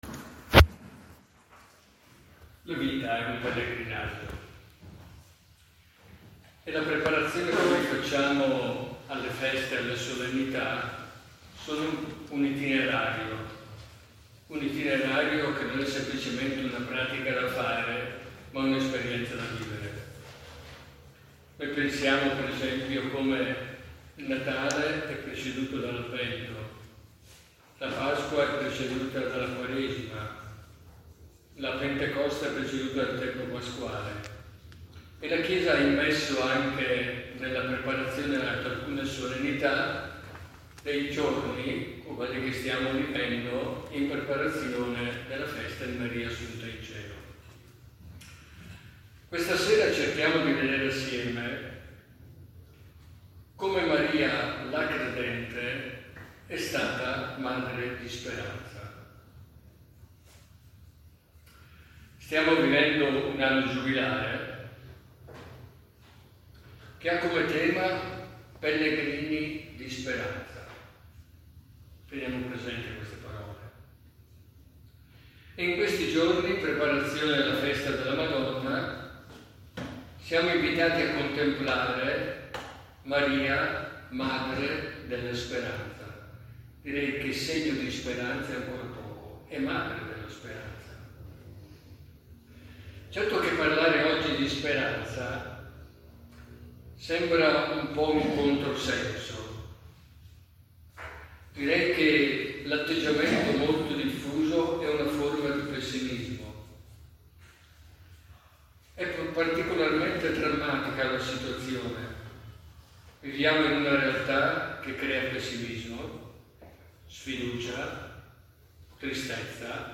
Celebrazione del 10 agosto 2025